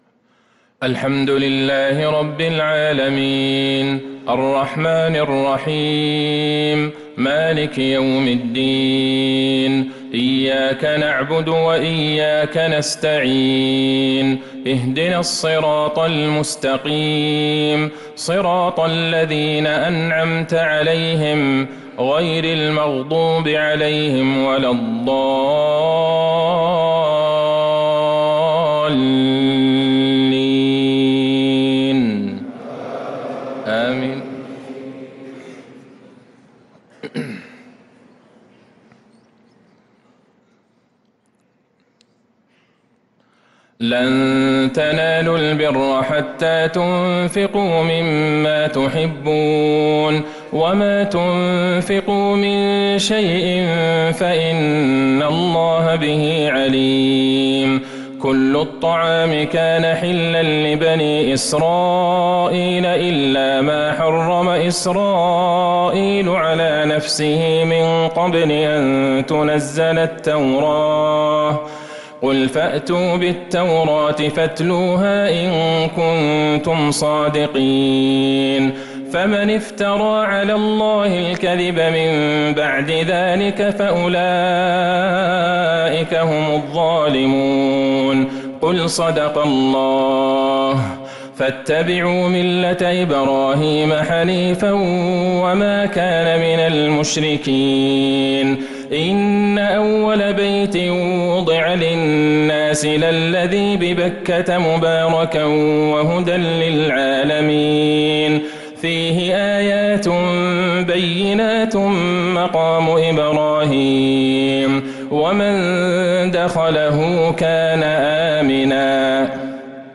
عشاء الأربعاء 7 محرم1447هـ | من سورة آل عمران 92-109 | Isha prayer from Surat Aal-E-Imran 2-7-2025 > 1447 🕌 > الفروض - تلاوات الحرمين